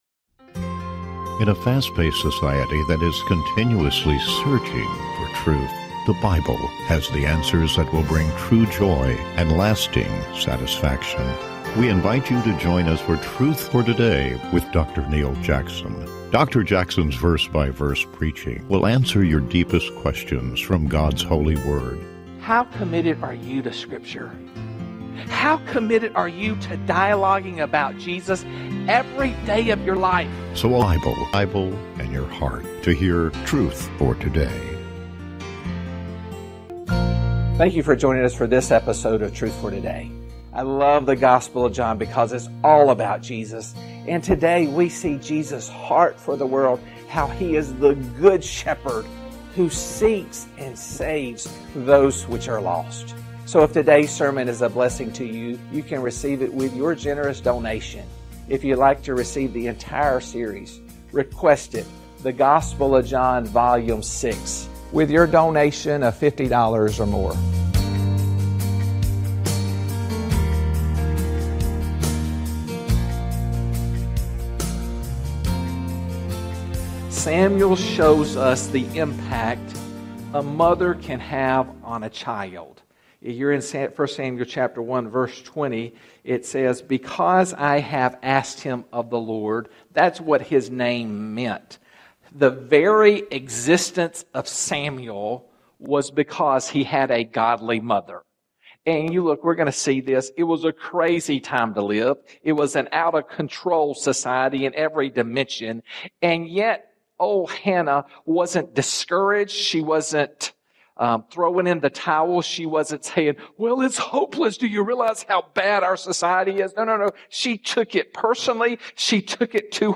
Truth for Today is committed to providing a broadcast that each week expounds God’s Word in a verse-by-verse chapter-by-chapter format.